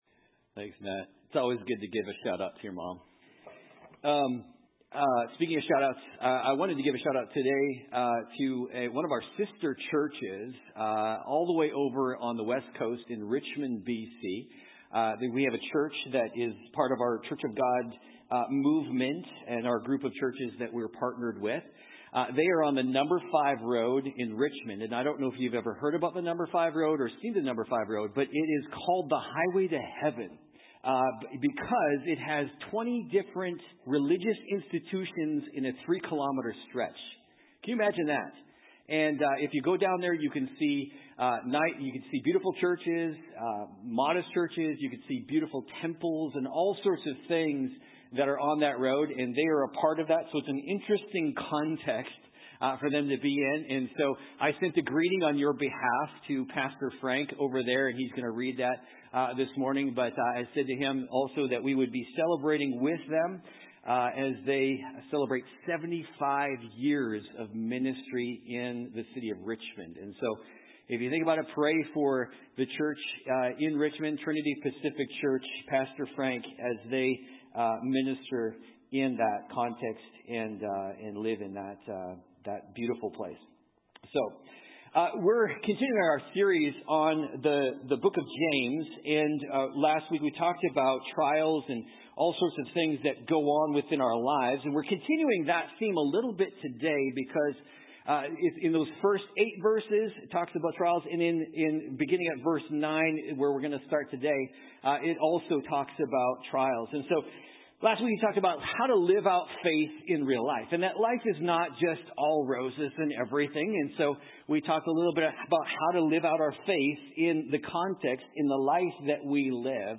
MP3 Notes Sermons in this Series The Struggle With Prayer How Long Is This Going To Take?